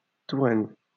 Touraine (US: /tuˈrn, tuˈrɛn/;[1][2][3] French: [tuʁɛn]